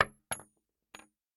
rifle_wood_5.ogg